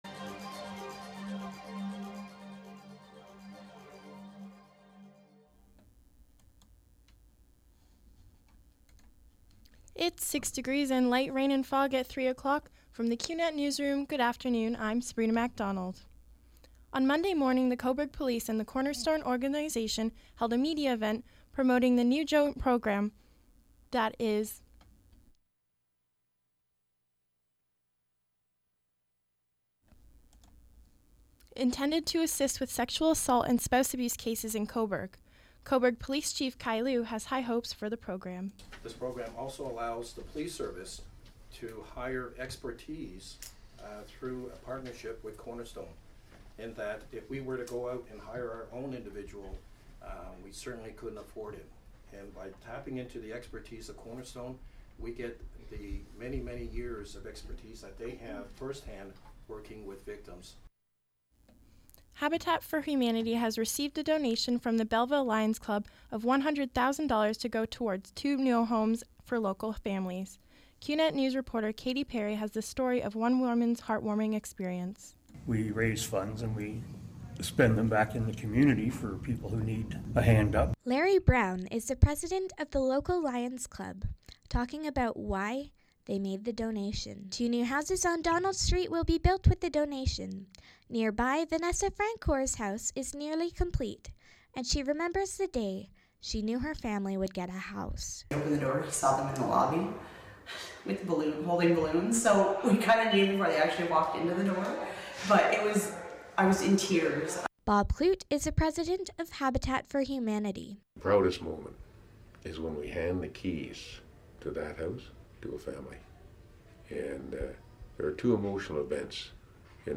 91X FM Newscast: Thursday April 6, 2017, 4 p.m